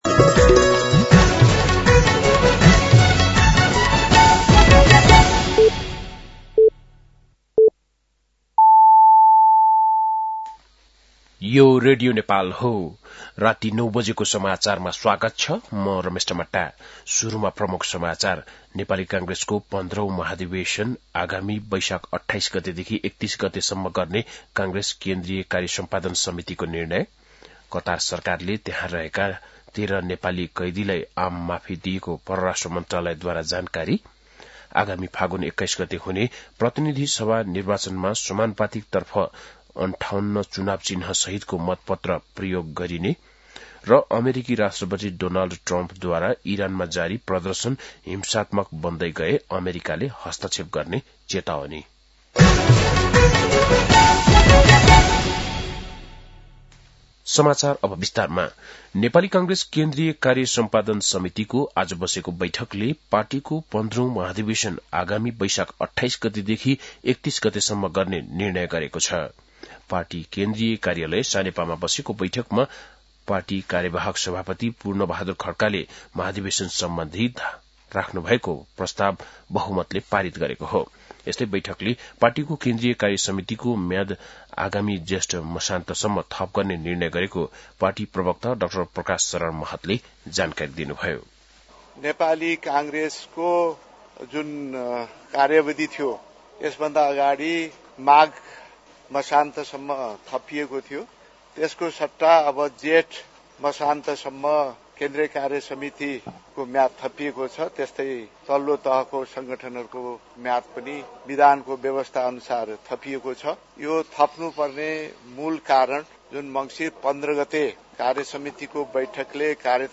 बेलुकी ९ बजेको नेपाली समाचार : १९ पुष , २०८२